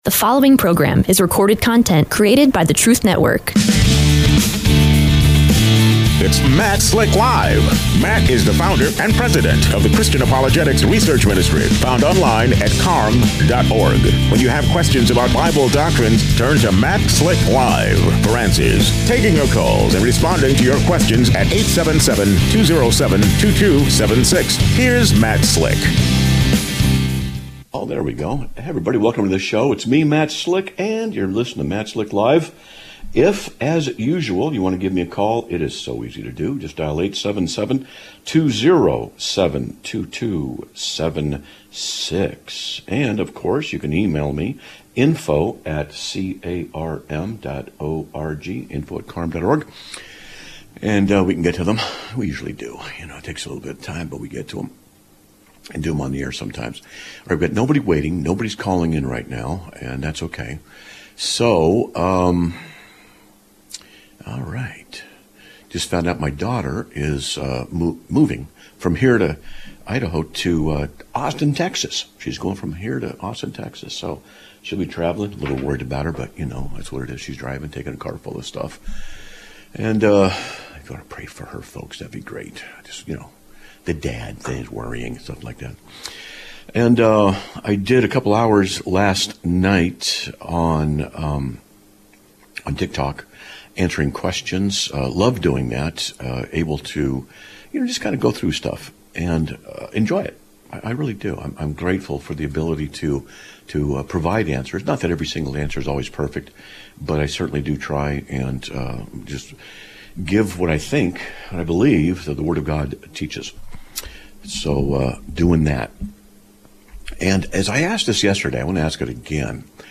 Live Broadcast of 02/11/2026